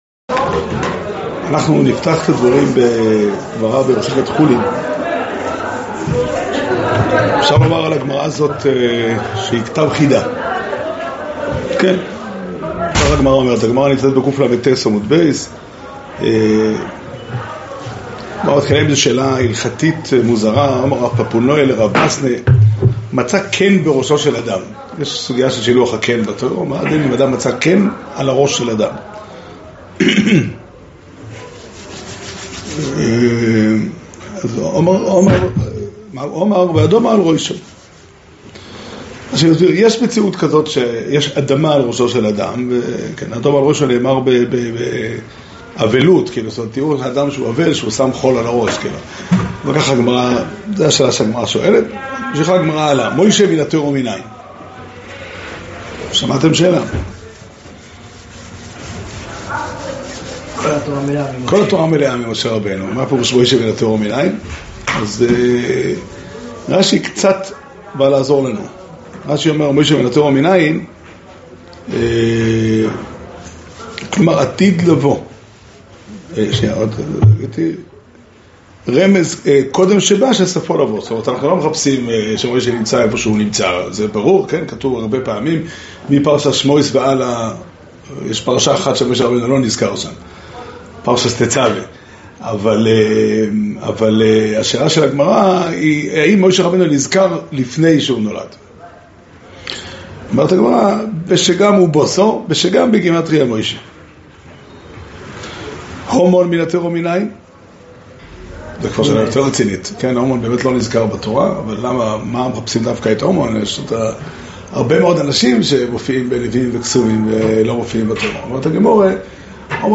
שיעור שנמסר בבית המדרש פתחי עולם